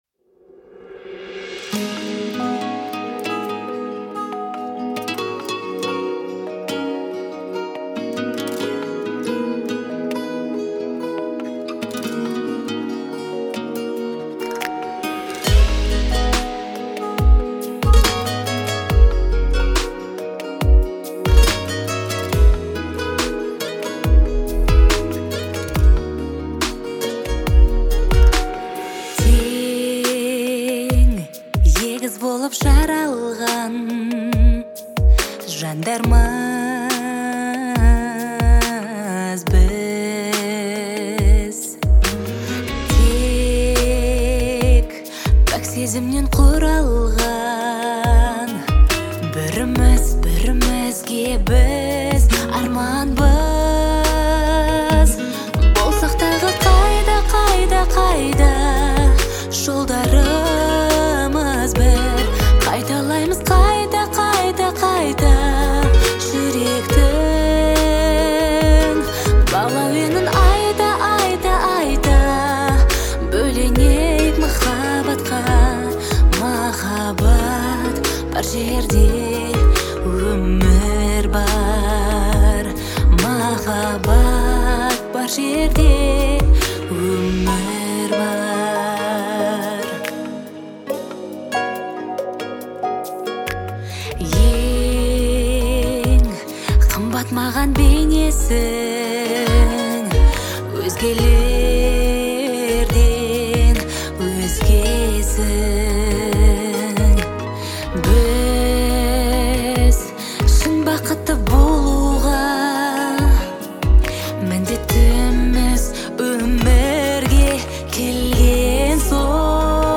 это яркий образец казахской поп-музыки